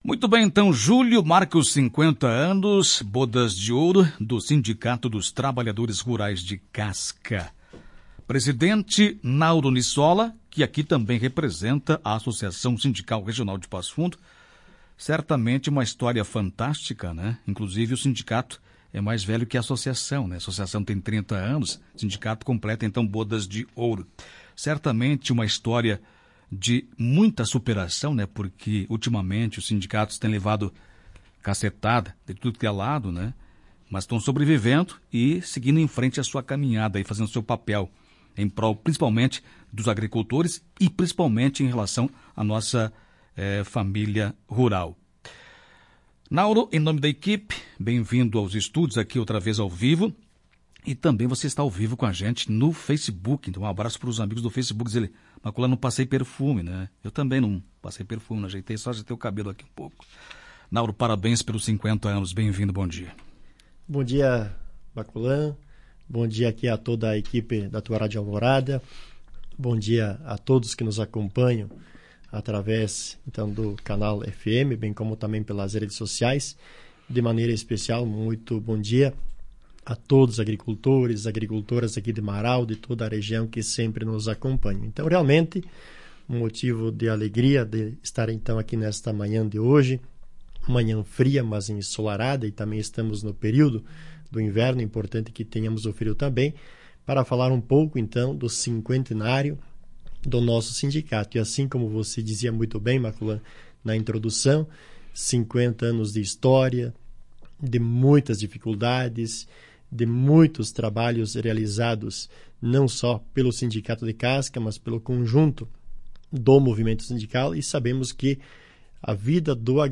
Em entrevista a Tua Rádio